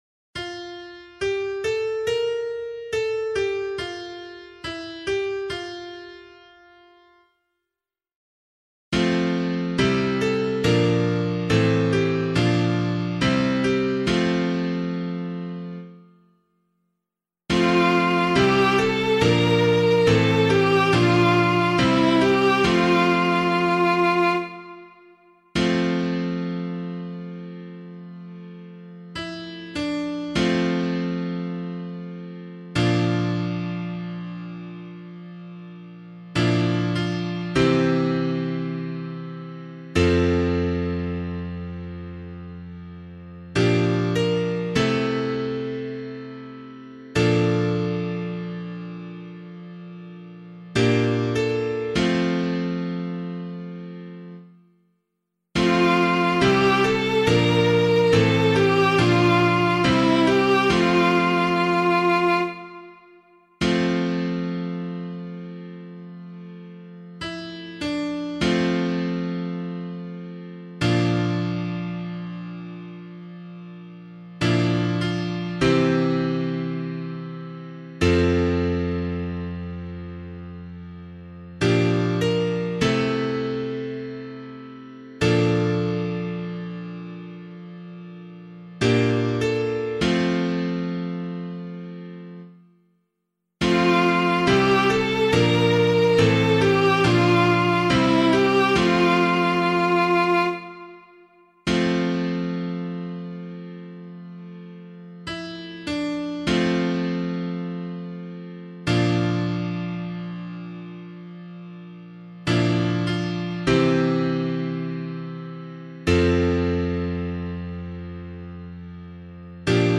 280 Peter and Paul Day Psalm [APC - LiturgyShare + Meinrad 6] - piano.mp3